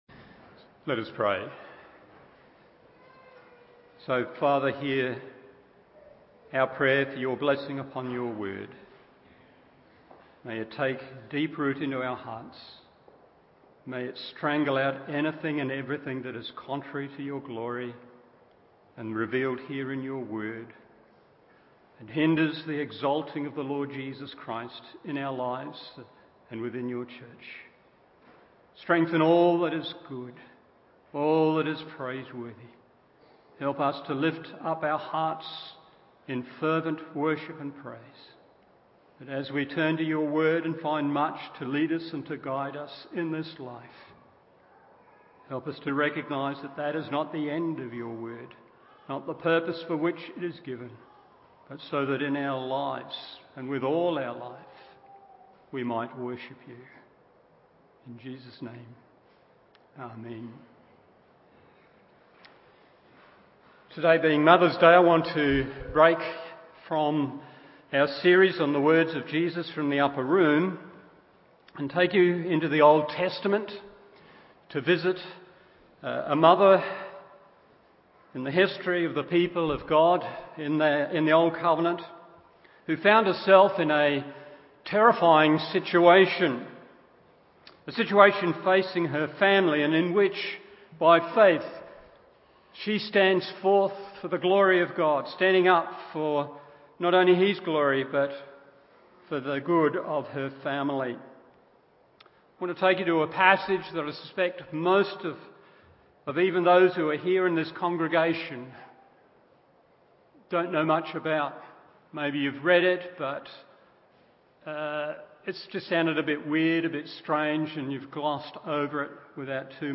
Morning Service Exodus 4:24-26 1. A Crisis from God 2. A Clarity of Grace 3. A Celebration of Good…